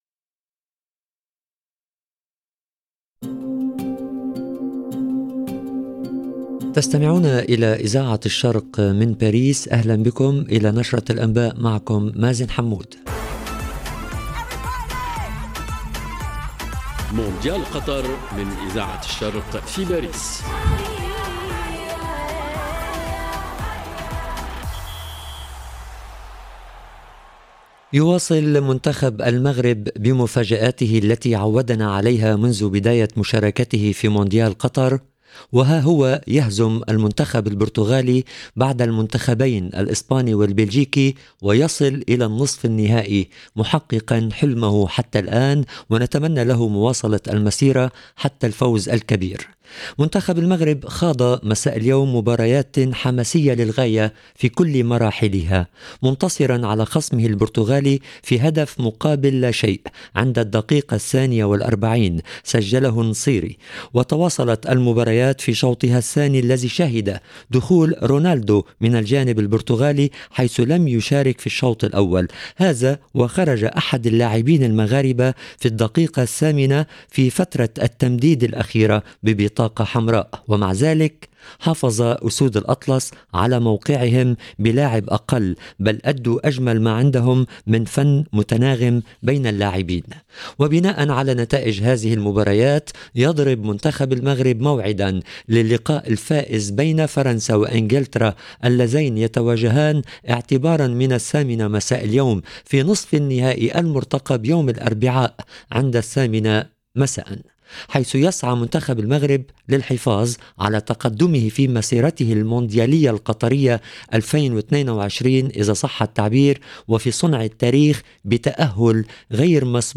LE JOURNAL DU SOIR EN LANGUE ARABE DU 10/12/22